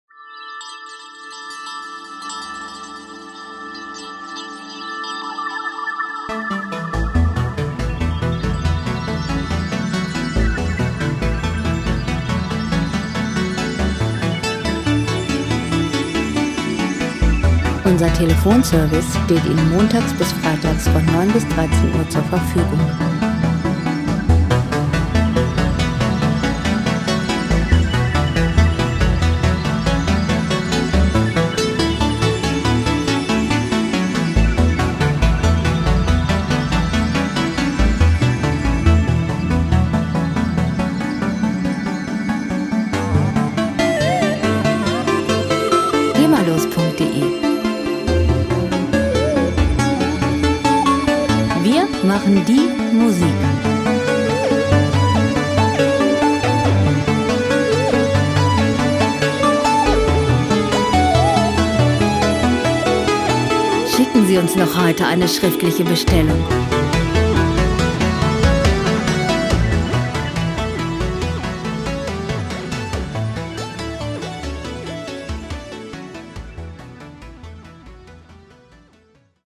Elektronische Musik - Moderne Welt
Musikstil: New Age
Tempo: 70 bpm
Tonart: A-Dur
Charakter: filigran, zerbrechlich
Instrumentierung: Synthesizer, Drumcomputer